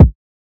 Kick (Hard).wav